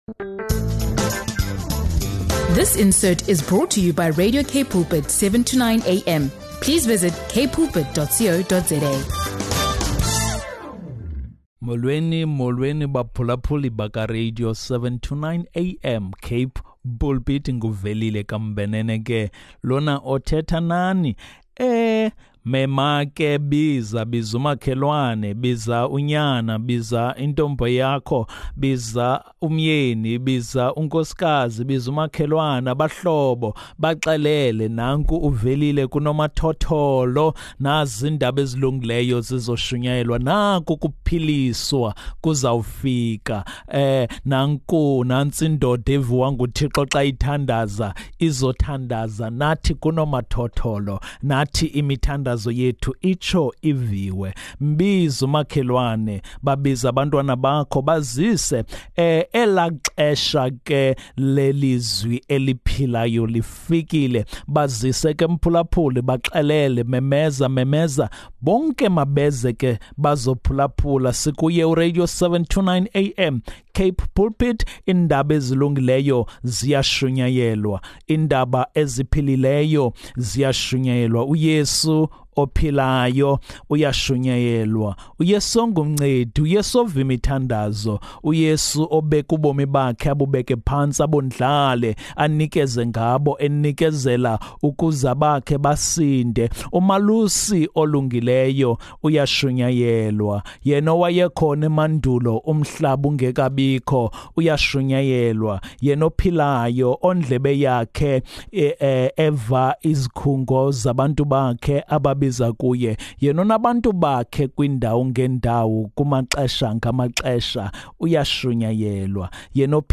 He offers a powerful prayer of gratitude for God’s love and grace, urging everyone to remain steadfast in their faith journey.